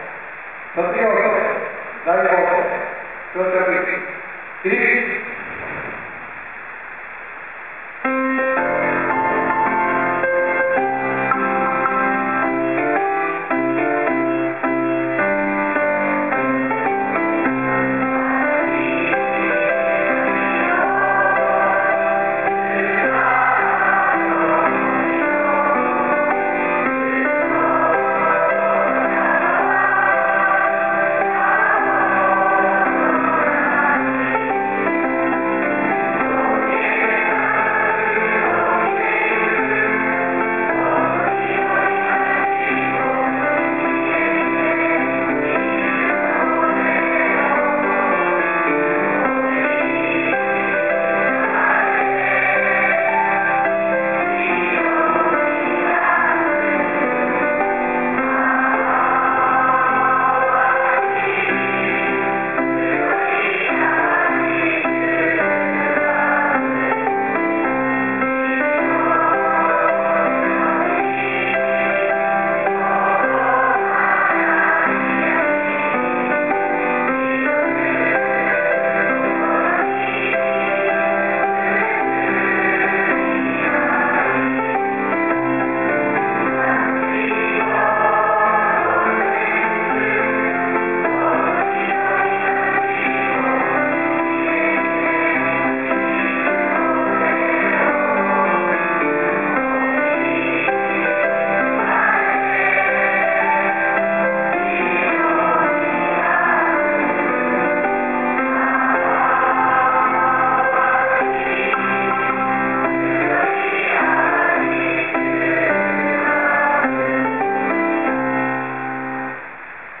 （※ 全校生徒による歌声 平成26年３月13日 録音)